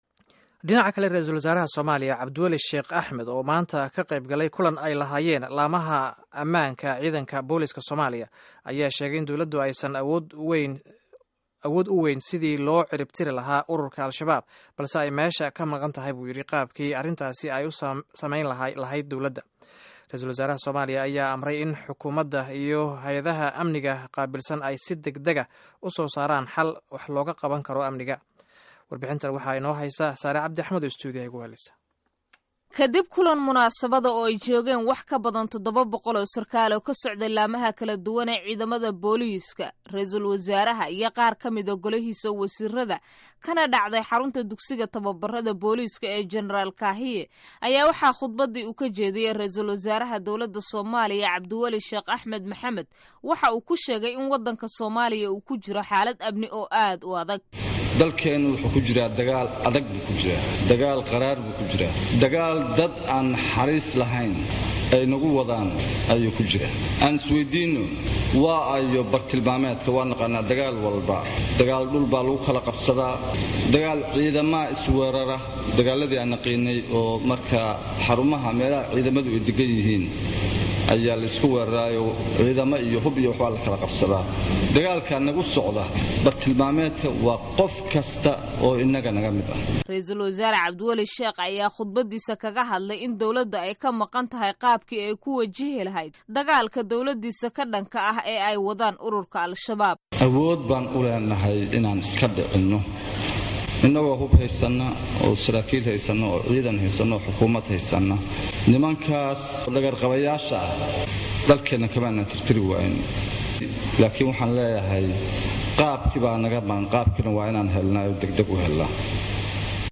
Dhageyso Warbixinta hadalka Ra'iisul-wasaaraha